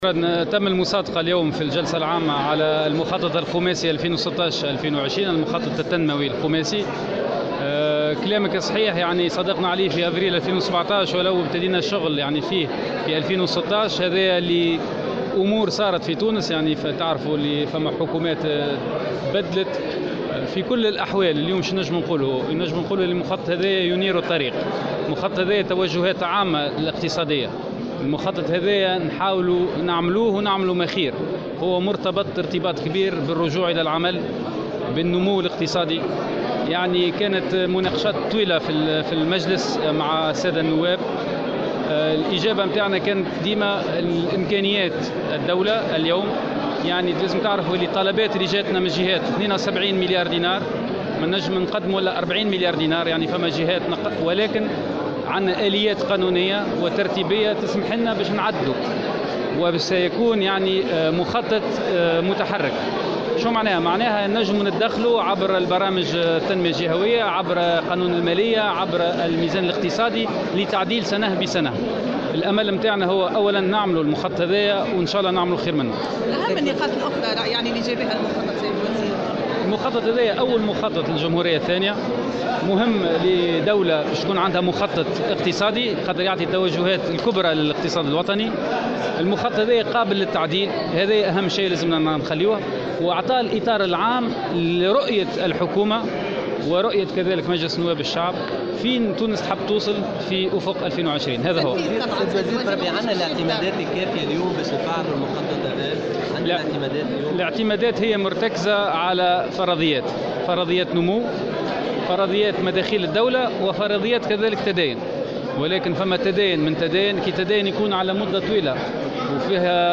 تم اليوم الأربعاء في جلسة عامة المصادقة على المخطط التنموي الخماسي 2016- 2020 ، بحسب ما أكده في تصريحات وزير الاستثمار والتعاون الدولي فاضل عبد الكافي.